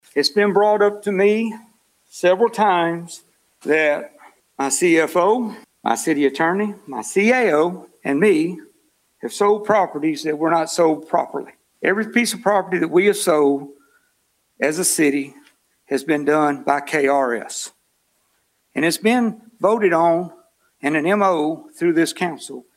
Meanwhile, Mayor JR Knight presented Council with several documents outlining the properties sold by the city in recent years, following some comments from members on social media.